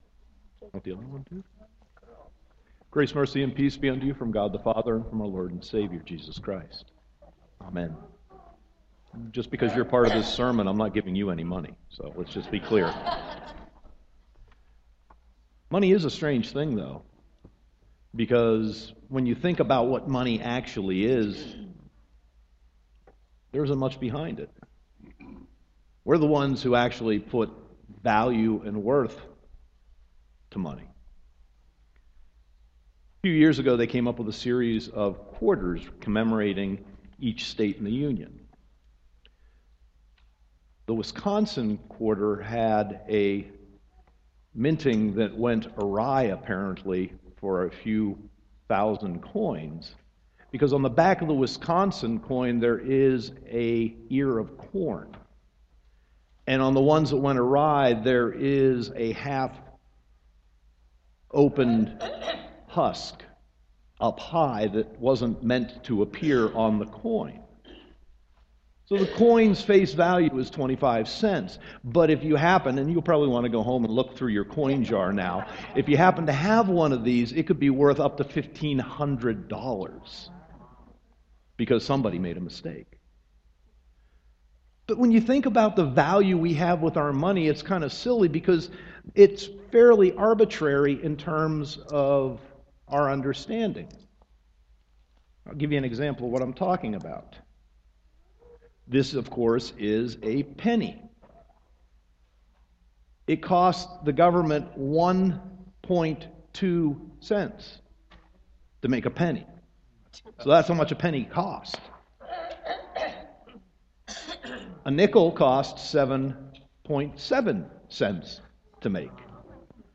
Sermon 10.19.2014